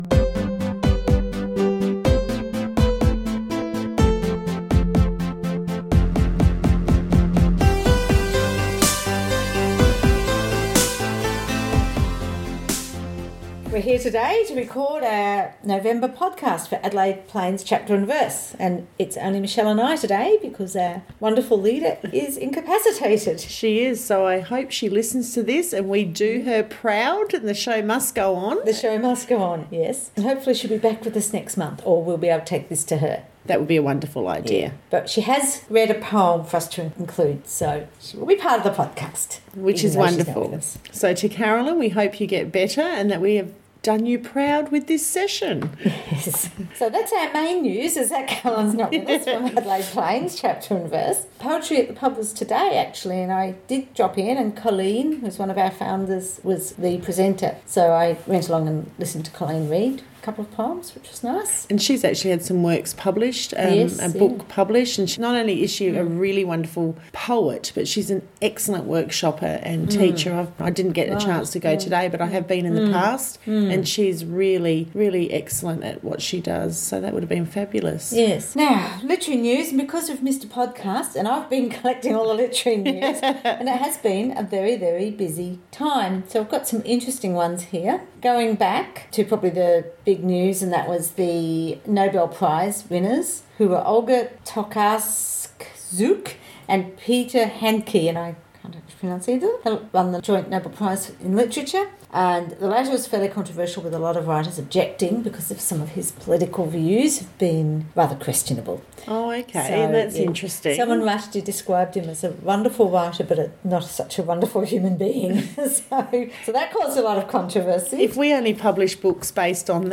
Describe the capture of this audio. Adelaide Plains Chapter and Verse monthly podcast recorded Thursday 21st of November at the Studio, Community House, Gawler.